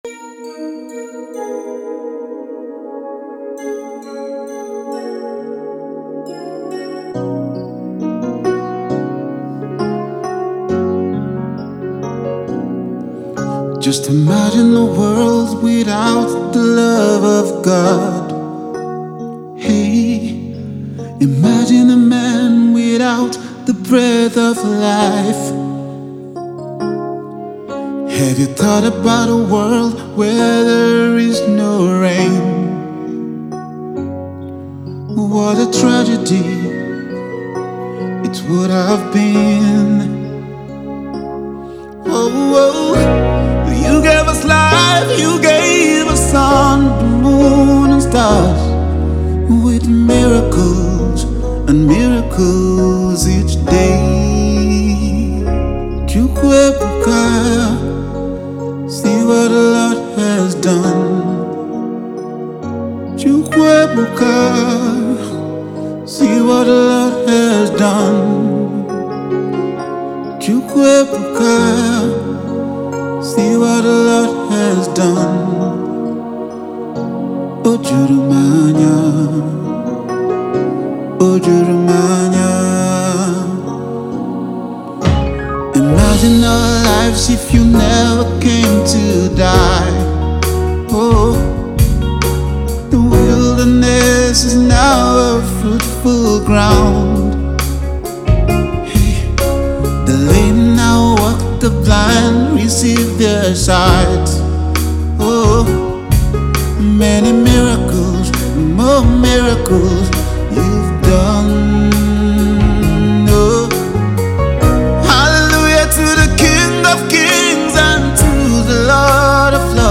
a very skilled Nigerian gospel singer
heartfelt song